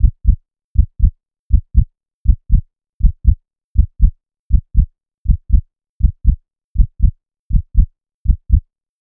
Fast Heartbeat Sound Effect Free Download
Fast Heartbeat